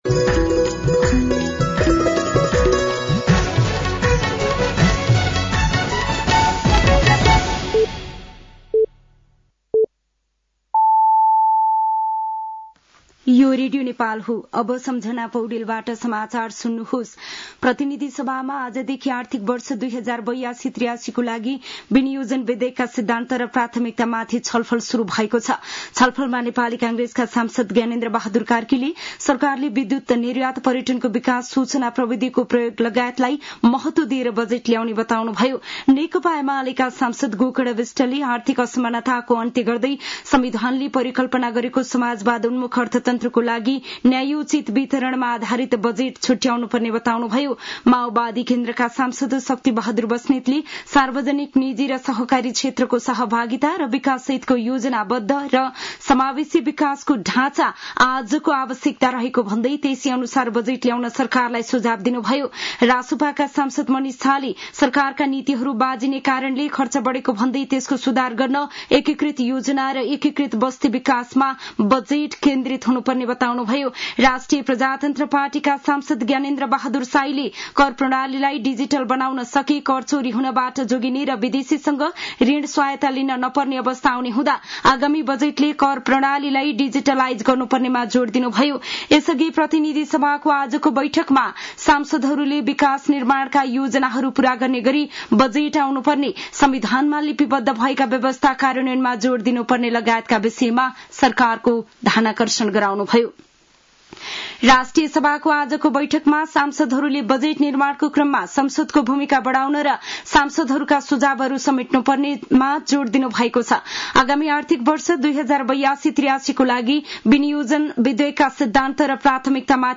साँझ ५ बजेको नेपाली समाचार : ३० वैशाख , २०८२